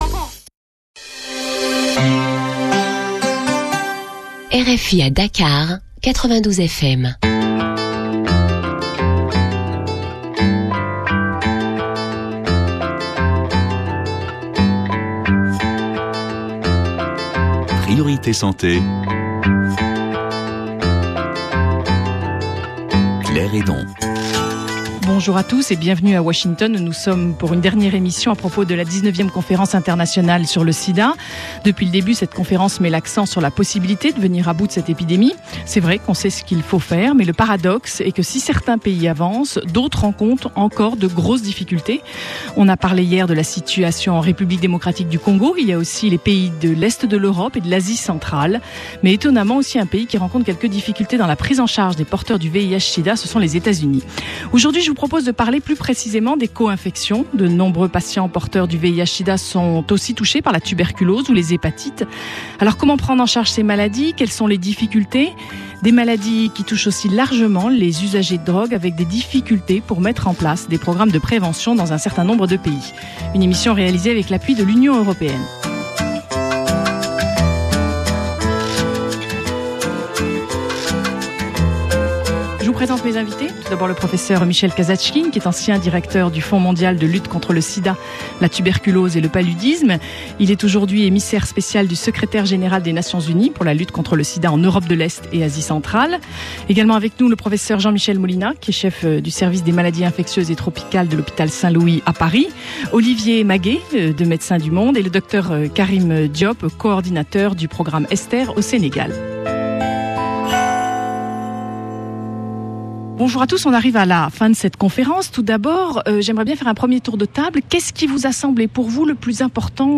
Dernière émission à l’occasion de la 19ème Conférence Internationale sur le sida. De nombreux patients porteurs du VIH sida sont aussi touchés par la tuberculose ou les hépatites. Comment prendre en charge ces maladies ?